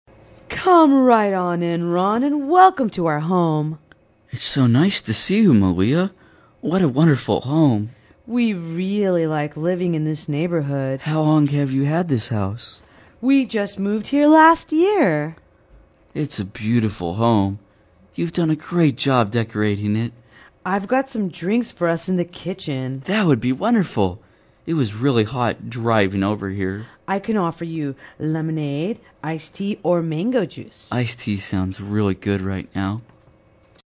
社交英语对话-Greeting a Visitor(3) 听力文件下载—在线英语听力室